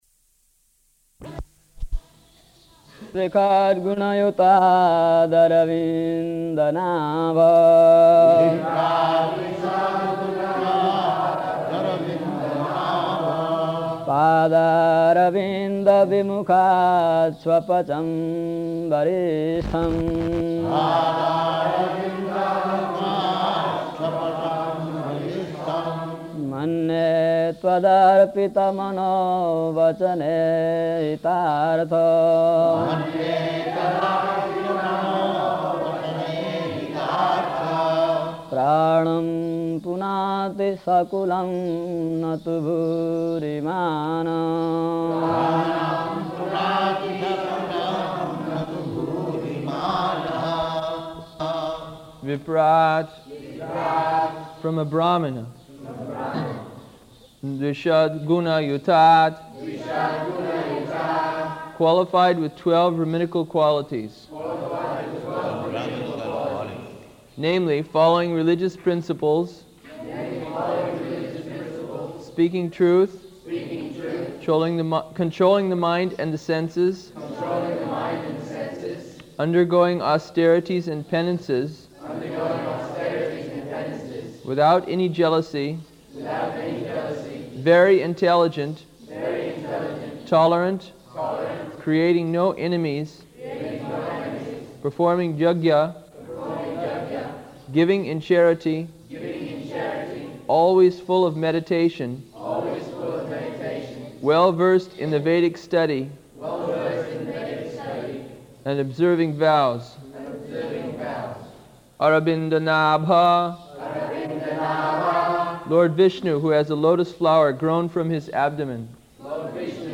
[chants synonyms; devotees chant responsively]
Location: Māyāpur
[chants verse, with Prabhupāda and devotees repeating]